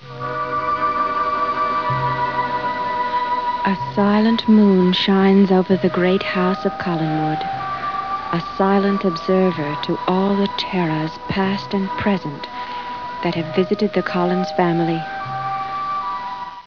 [6] Lara & Angelique Speak!  ( Wave files )
Snd.  - Lara introduces D.S. episode 480.   [ 179 KB ]